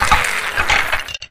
PixelPerfectionCE/assets/minecraft/sounds/mob/skeleton/death.ogg at mc116
death.ogg